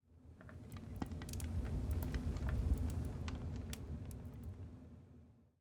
Minecraft Version Minecraft Version snapshot Latest Release | Latest Snapshot snapshot / assets / minecraft / sounds / block / blastfurnace / blastfurnace4.ogg Compare With Compare With Latest Release | Latest Snapshot
blastfurnace4.ogg